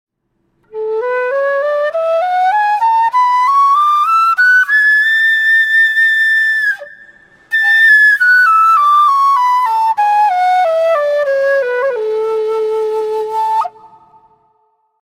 Кена (Quena, Ramos, A) Перу
Кена (Quena, Ramos, A) Перу Тональность: A
Материал: тростник
Кена - продольная флейта открытого типа, распространённая в южноамериканских Андах.